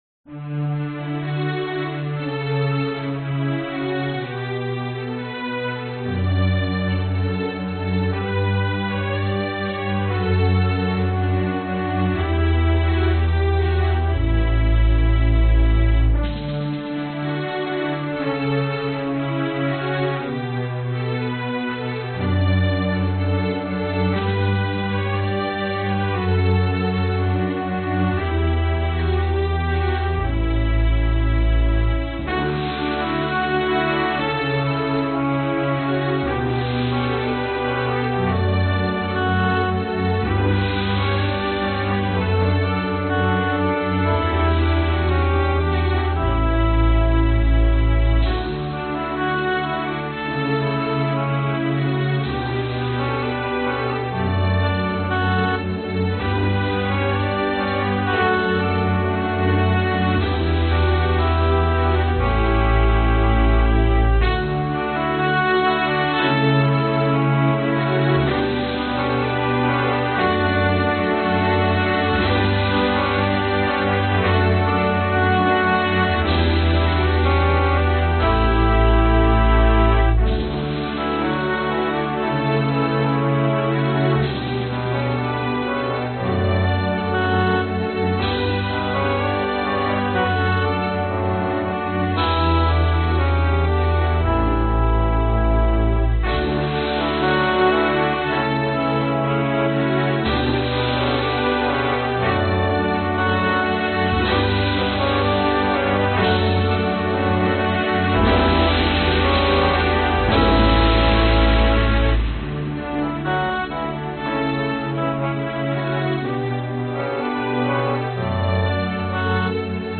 Distort Loops " Metalic Slam
描述：使用第三方虚拟失真单位在Sylenth 1中制作的声音。
标签： 电子 的Dubstep 环境 电影 音乐 免费 旅游 电影 古典 DJ 音响
声道立体声